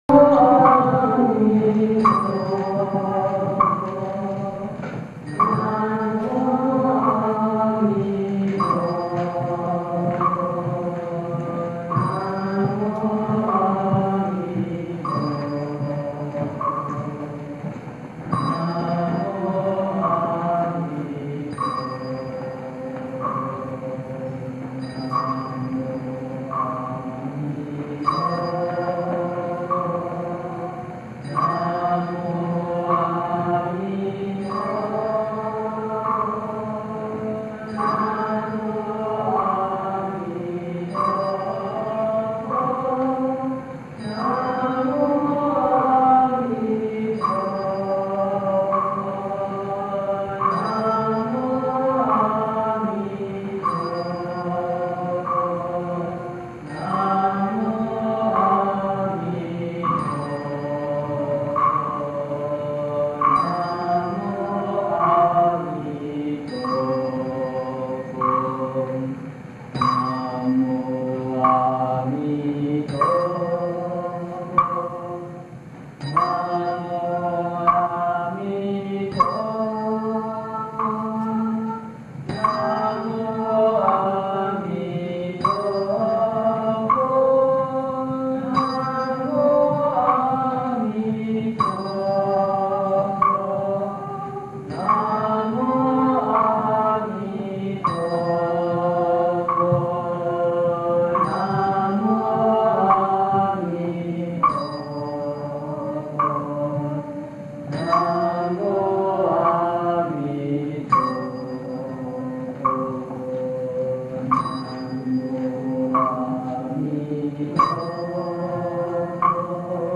This is the last of the daily recitations at the close of day at DRBA's monasteries.